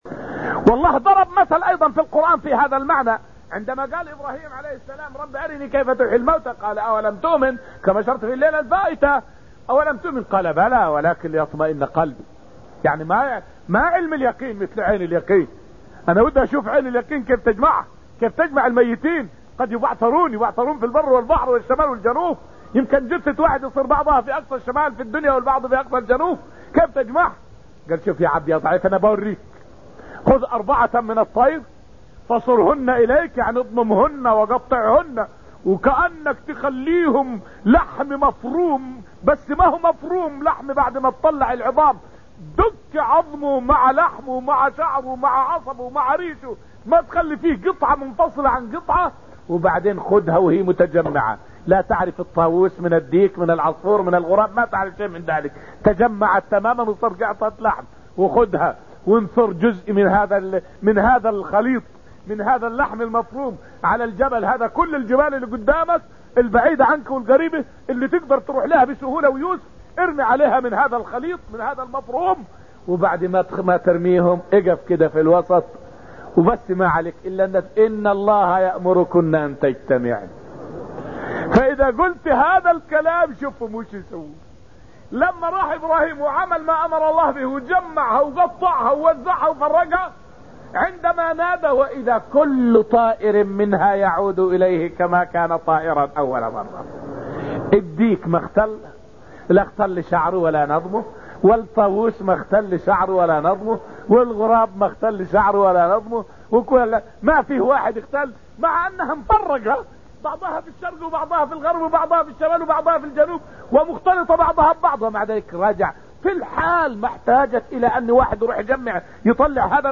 فائدة من الدرس الرابع من دروس تفسير سورة القمر والتي ألقيت في المسجد النبوي الشريف حول نبي الله إبراهيم وطلبه اطمئنان القلب.